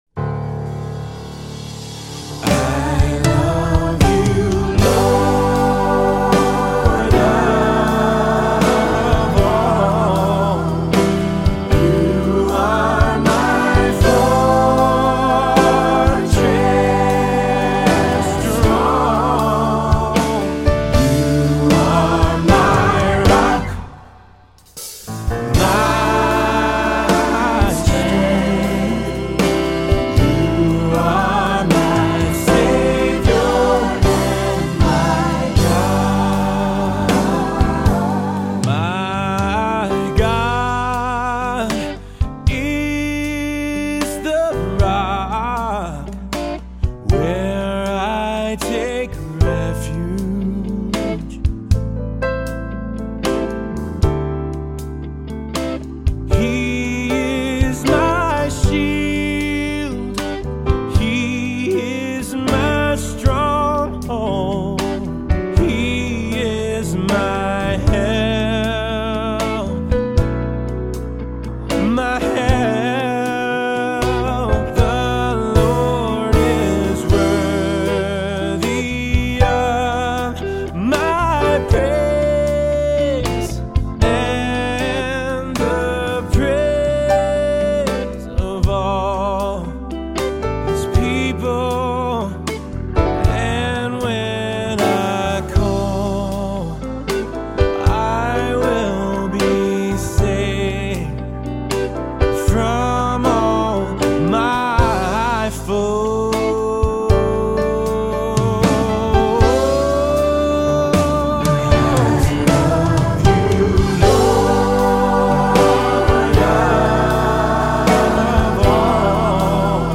Voicing: SATB, assembly